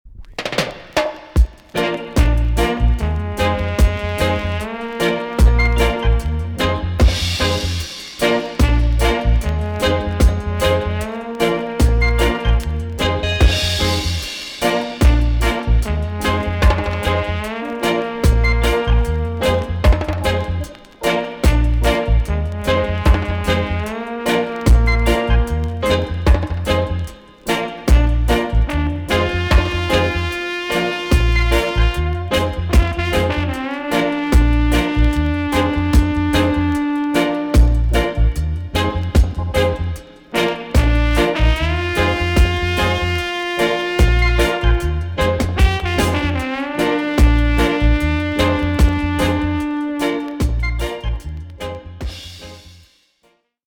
TOP >REGGAE & ROOTS
B.SIDE Version
EX 音はキレイです。